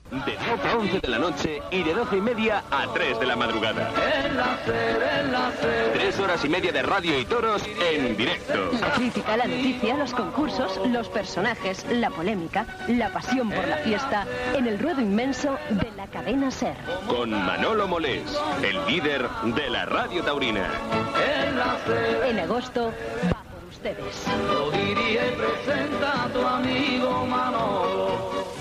944e65d2edbbf049f83b66751fa51fcd12b6a3a8.mp3 Títol Cadena SER Emissora Ràdio Barcelona Cadena SER Titularitat Privada estatal Nom programa Los toros Descripció Promoció del programa.
Gravació realitzada a València.